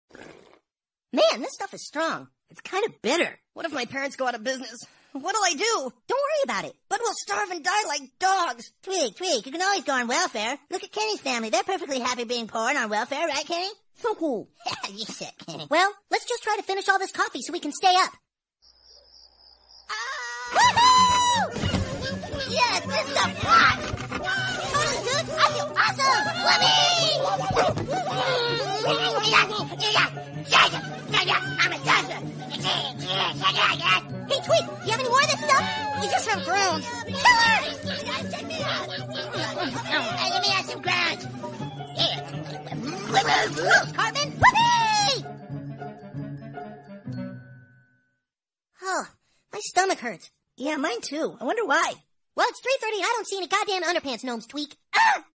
tweek's voice is so cute and tender